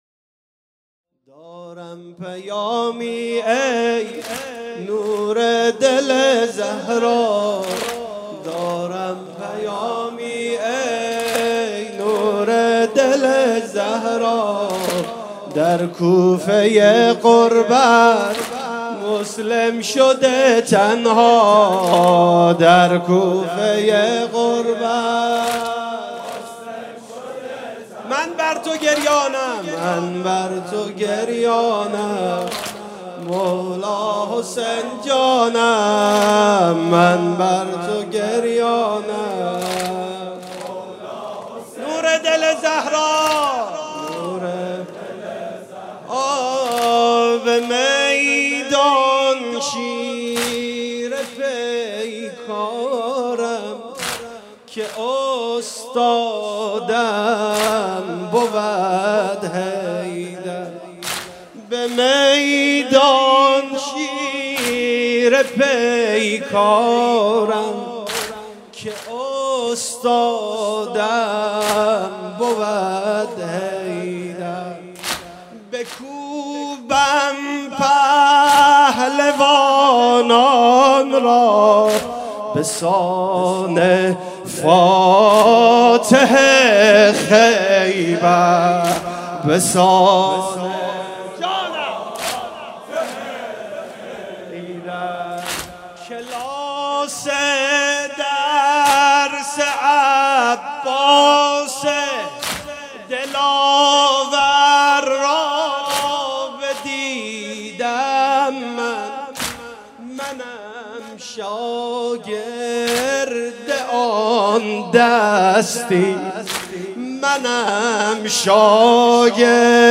شب پنجم محرم 97 - هیئت شبان القاسم - دارم پیامی ای نور دل زهرا